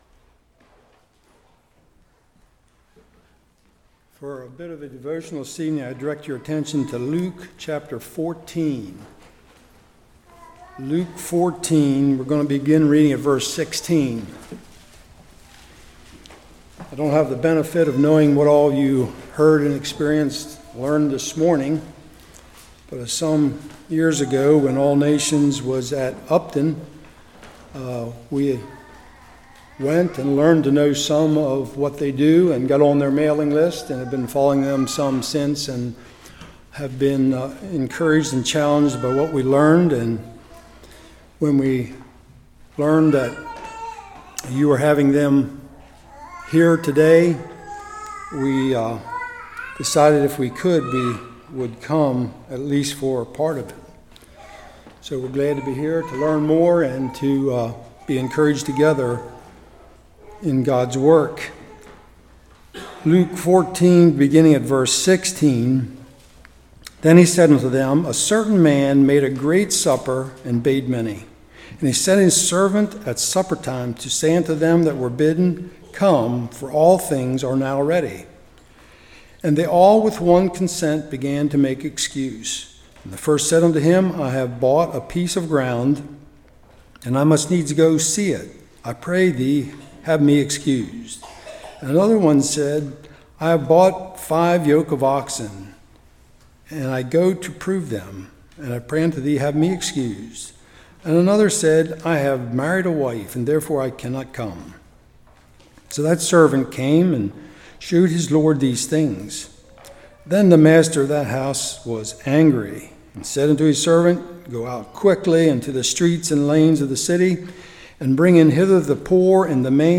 Luke 14:16-24 Service Type: Evening Who is responsible to reach the unreached?